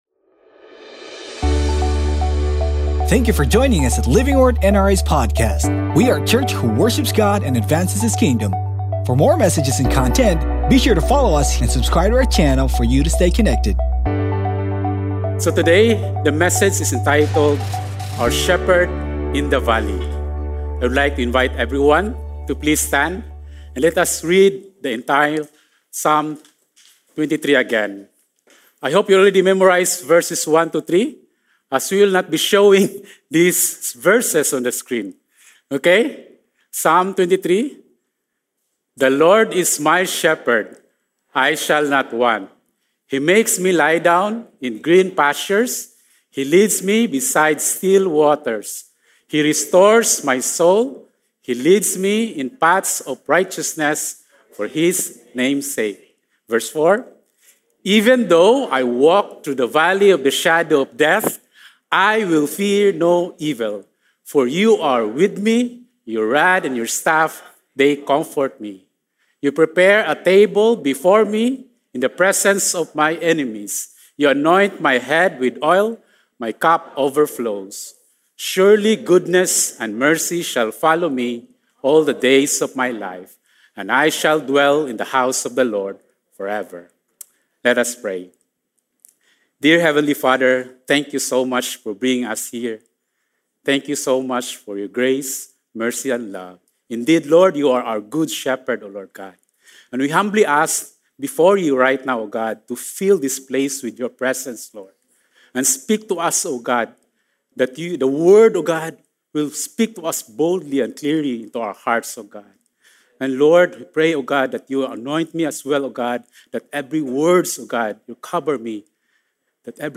Sermon Title: OUR SHEPHERD IN THE VALLEY